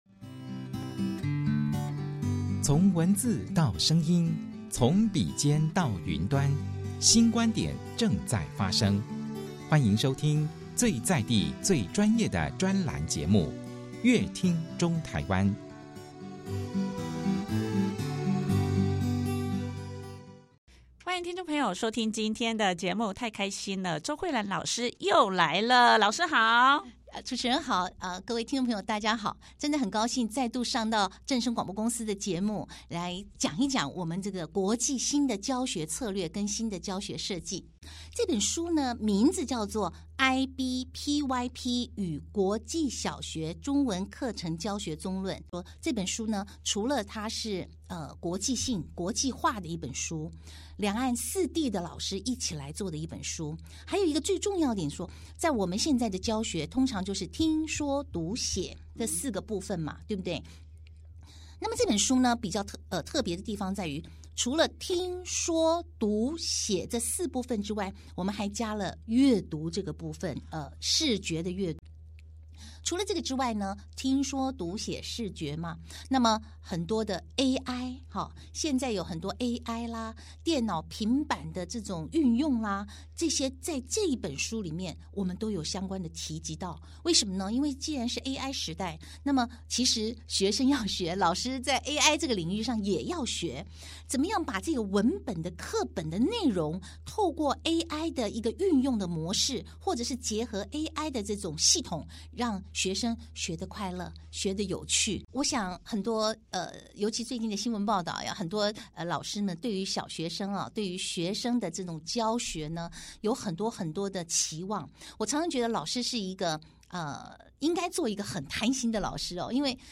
在這一集專訪中